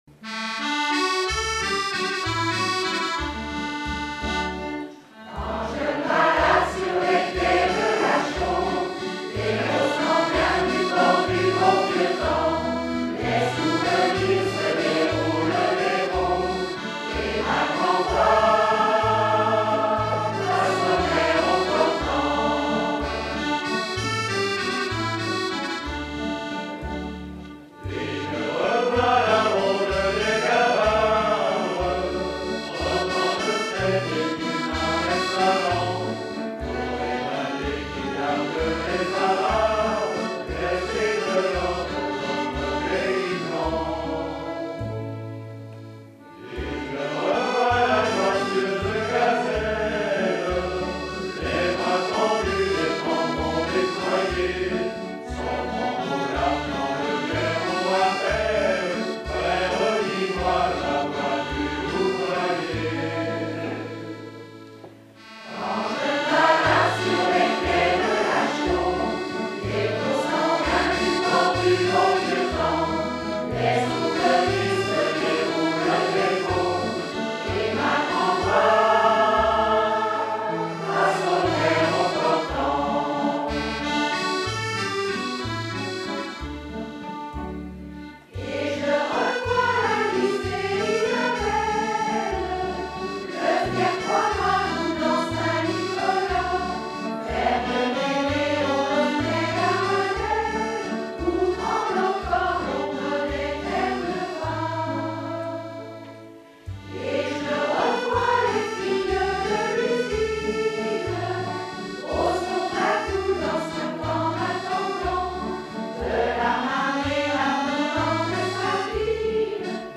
Veillées Chaumoises choeur
Genre strophique
Pièce musicale inédite